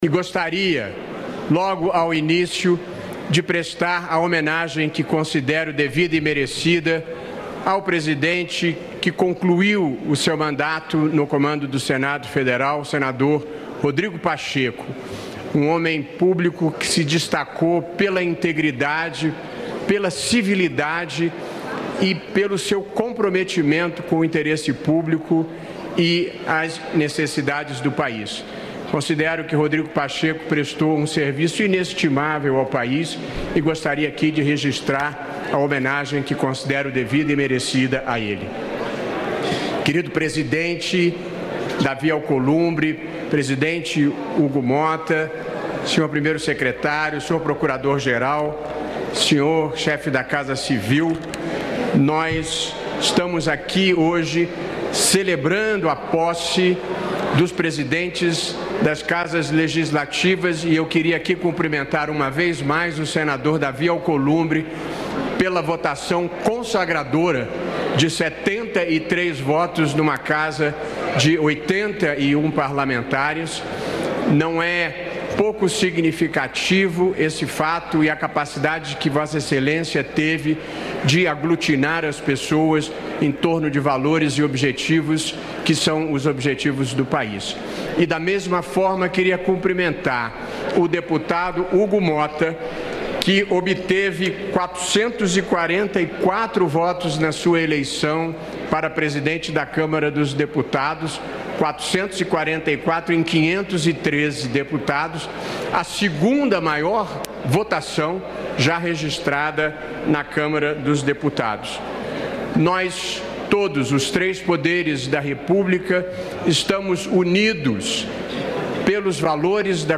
Pronunciamento do presidente do Supremo Tribunal Federal
O presidente do Supremo Tribunal Federal (STF), Luís Roberto Barroso, participou nesta segunda-feira (3) da sessão solene de abertura do Ano Legislativo.